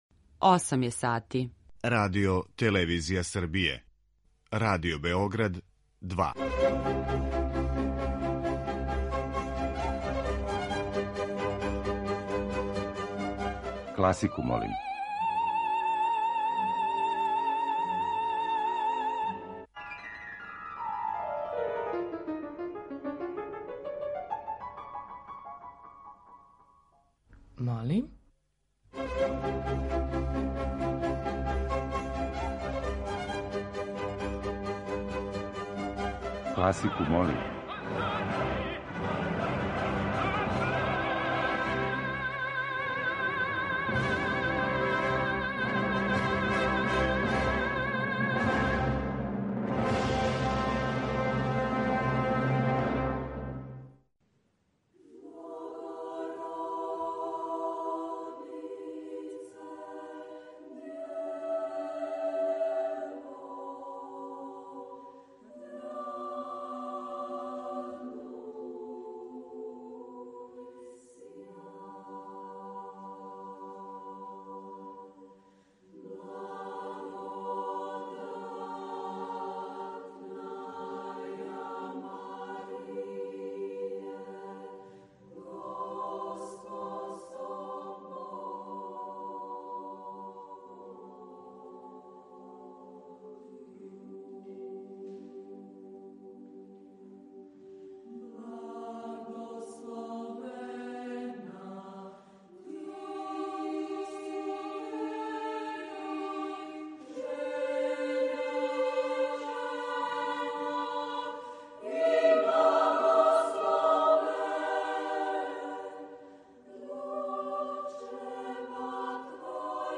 Избор за топ-листу класичне музике Радио Београда 2